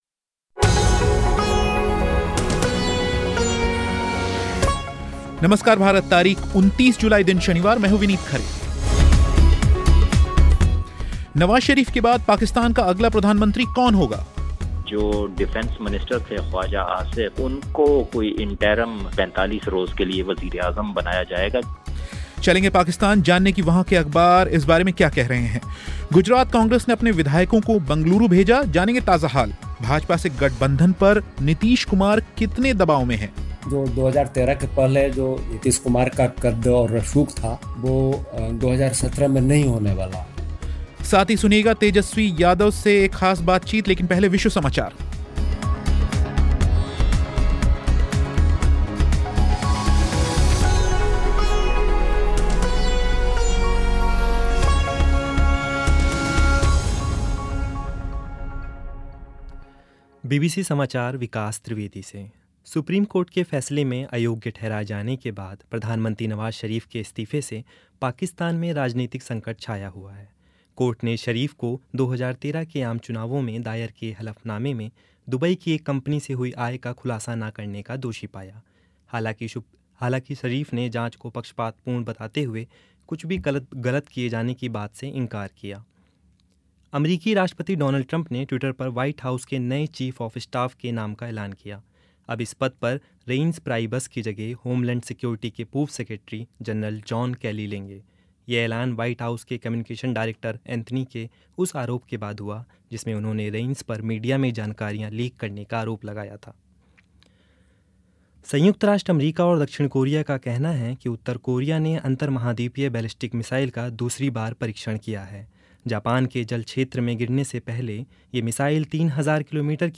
गुजरात कांग्रेस ने अपने विधायकों को बंगलूरू भेजा. जानेंगे ताजा हाल. भाजपा से गठबंधन पर नीतीश कुमार कितने दबाव में. साथ ही तेजस्वी यादव से बातचीत लेकिन पहले विश्व समाचार.